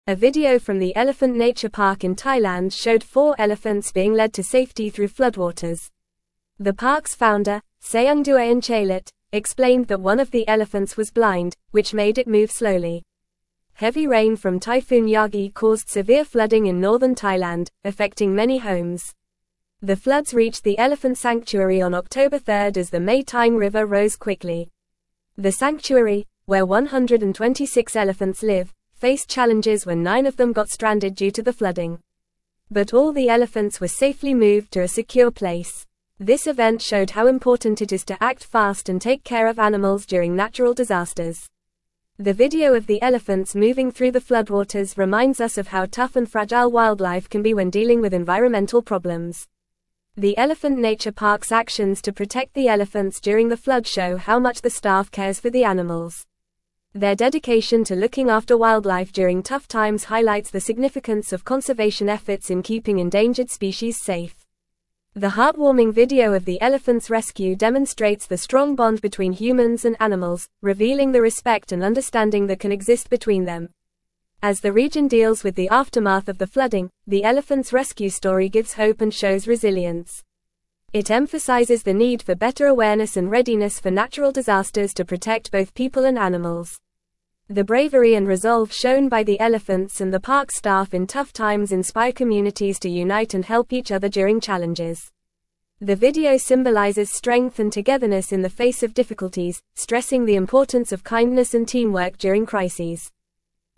Fast
English-Newsroom-Upper-Intermediate-FAST-Reading-Elephants-rescued-from-floodwaters-in-Thailand-sanctuary.mp3